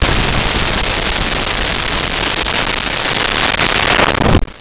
Rocket Launcher 1
Rocket Launcher_1.wav